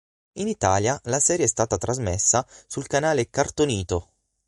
An user Italian